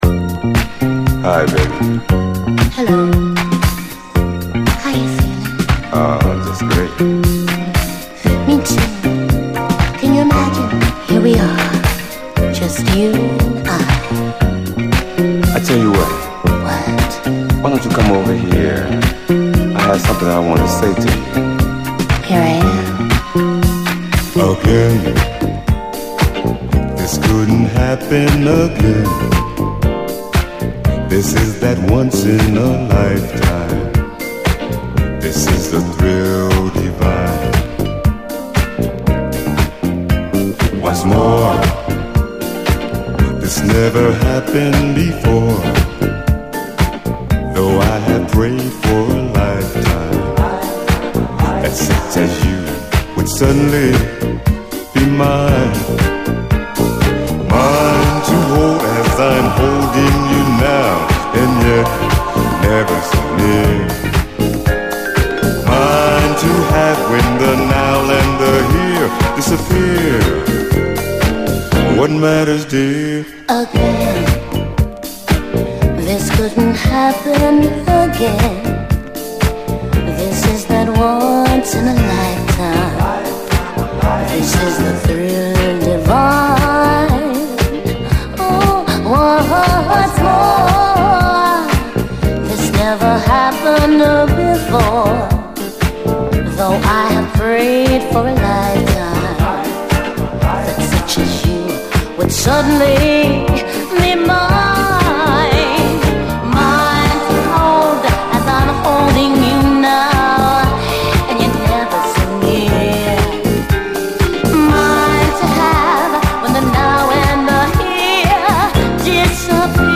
SOUL, 70's～ SOUL, DISCO, 7INCH
ロマンティックなフィリー・ダンサー！